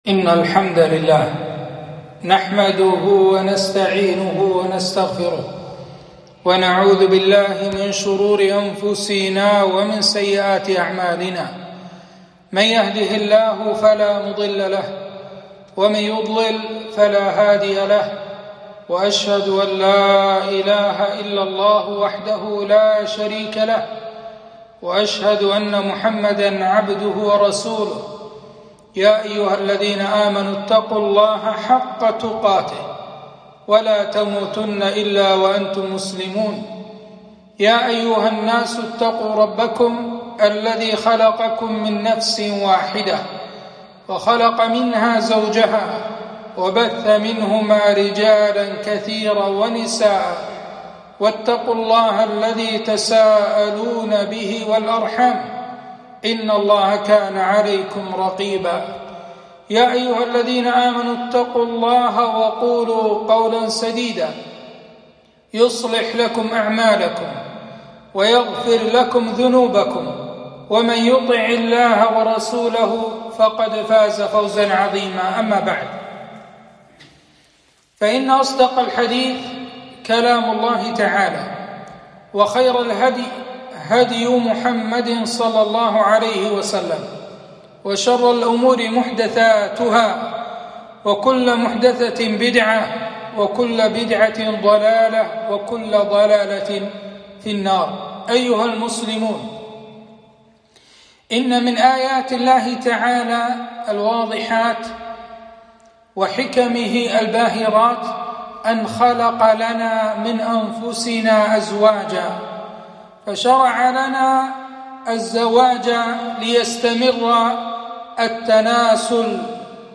خطبة - الطلاق أسبابه وعلاجه